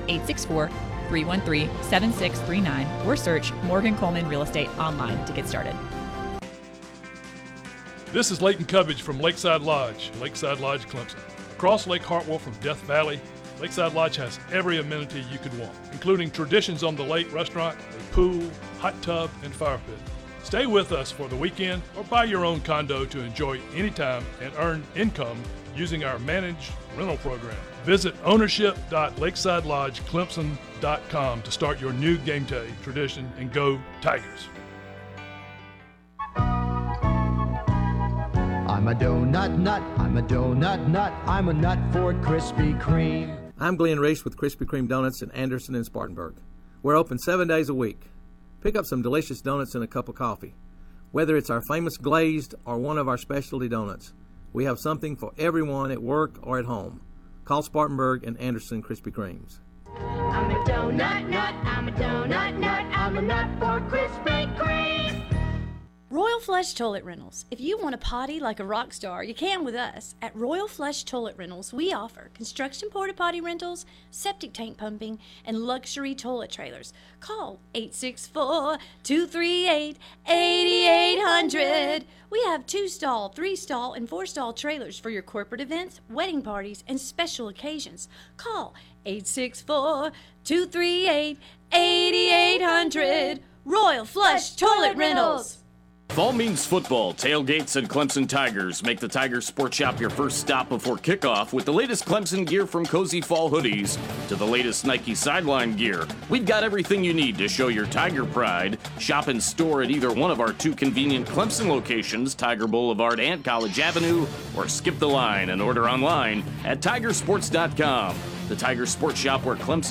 He brings his fiery and passionate personality to the airwaves every day, entertaining listeners with witty comments, in depth analysis and hard-hitting interviews.